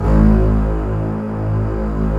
Index of /90_sSampleCDs/Roland L-CD702/VOL-1/STR_Cbs Arco/STR_Cbs1 f